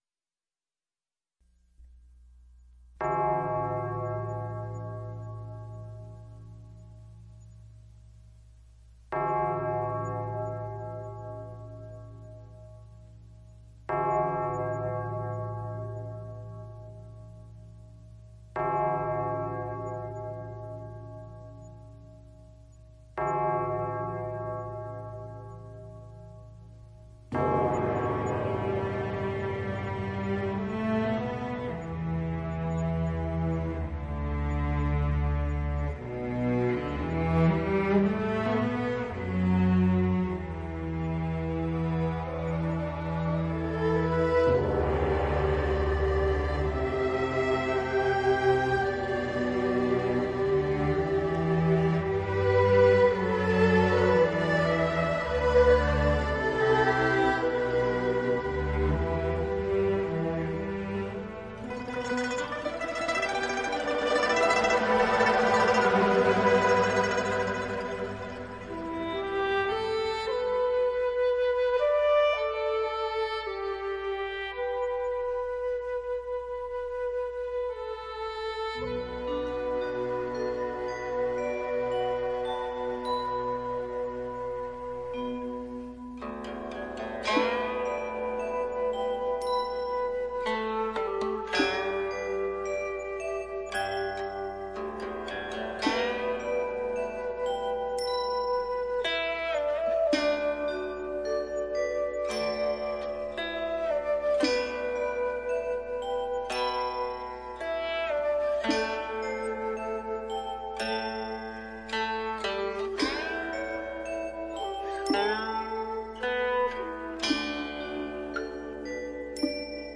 佛音 冥想 佛教音乐 返回列表 上一篇： 汉文心经